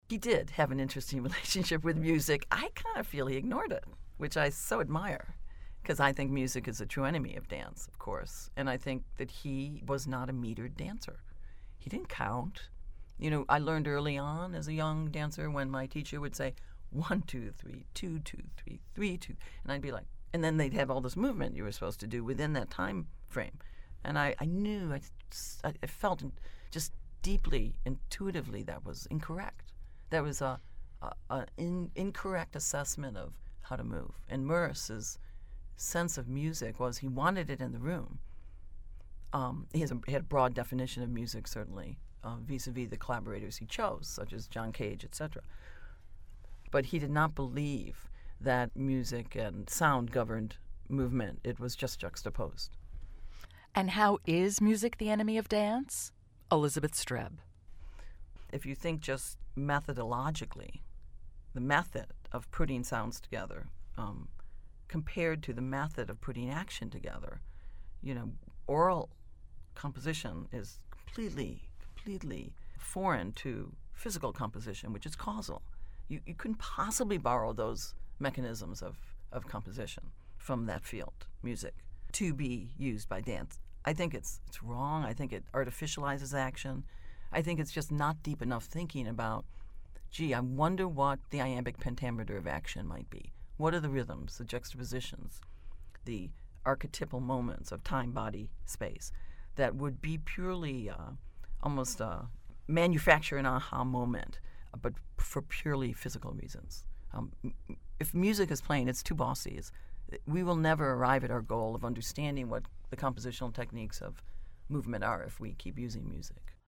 Here's a brief audio clip of Elizabeth Streb talking about Merce's unusual relationship with music.